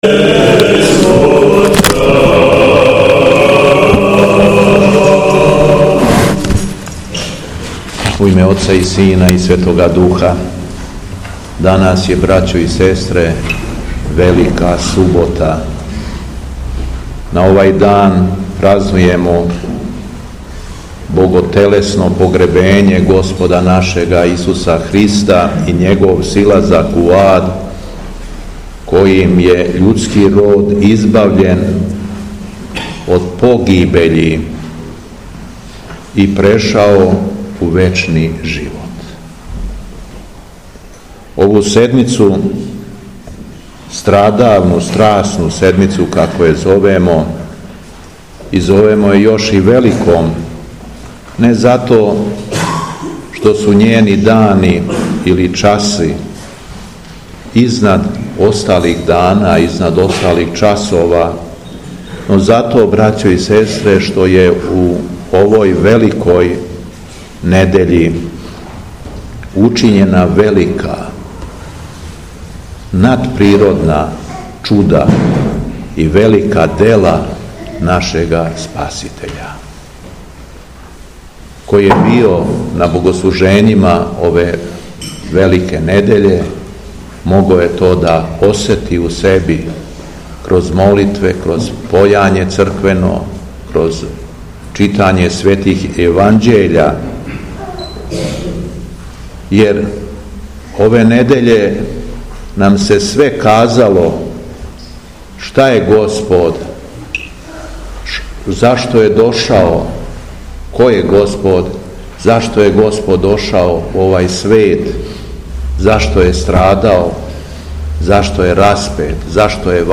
Беседа Његовог Високопреосвештенства Митрополита шумадијског г. Јована
Након прочитаног јеванђељске перикопе верном народу Божјем се надахнутом беседом обратио Митрополит Јован: